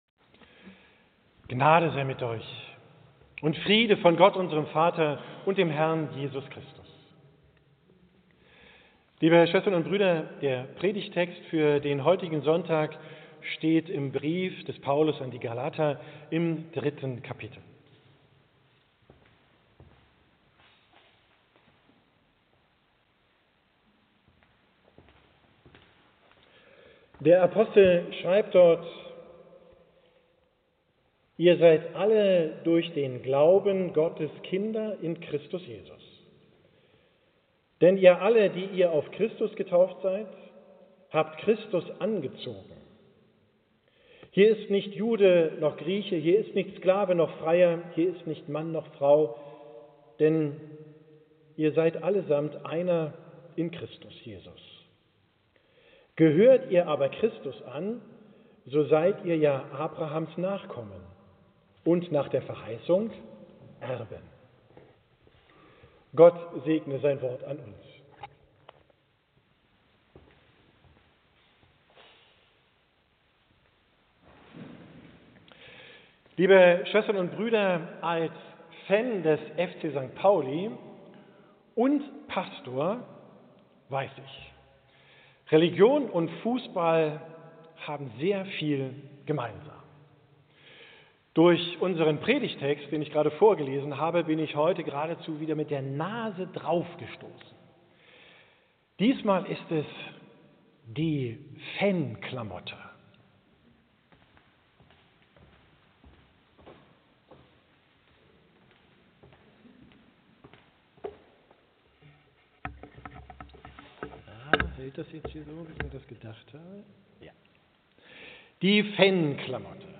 Perdigt vom 17. Sonntag nach Trinitatis, 22.
gehalten in St. Petri Altona